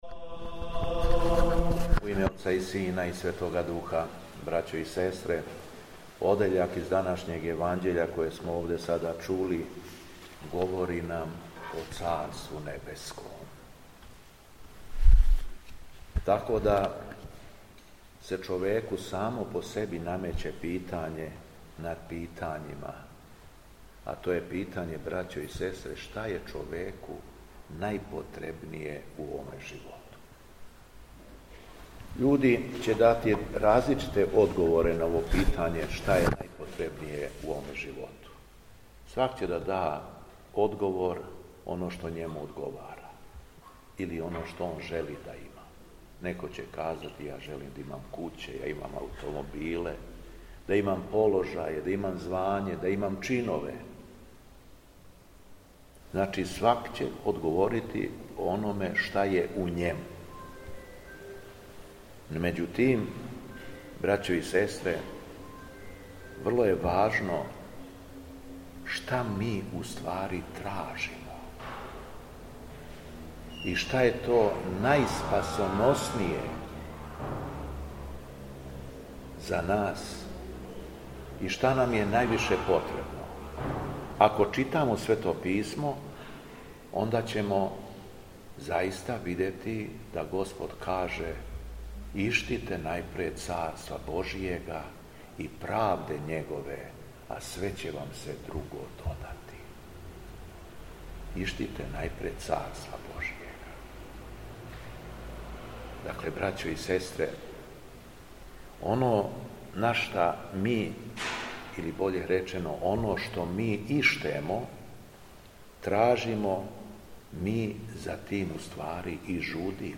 СВЕТА АРХИЈЕРЕЈСКА ЛИТУРГИЈА У ХРАМУ СВЕТОГ ЦАРА ЛАЗАРА У КРАГУЈЕВАЧКОМ НАСЕЉУ БЕЛОШЕВАЦ - Епархија Шумадијска
Беседа Његовог Високопреосвештенства Митрополита шумадијског г. Јована